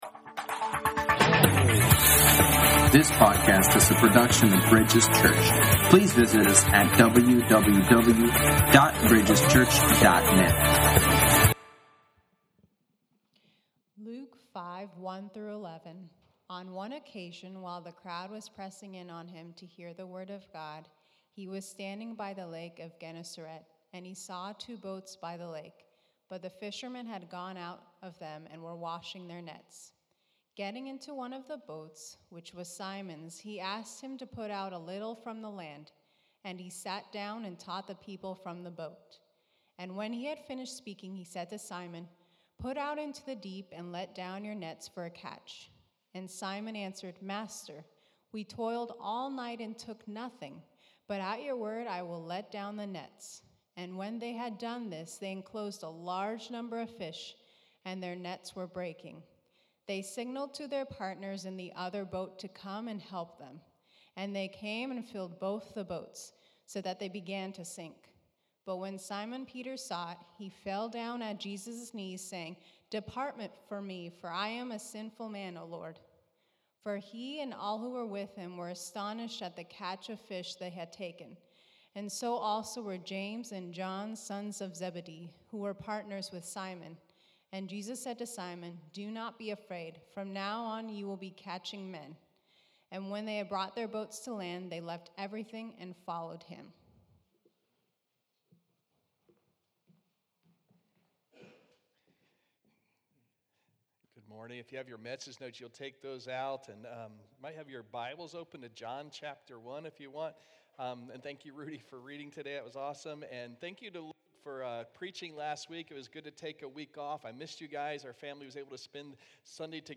Sermons | Bridges Church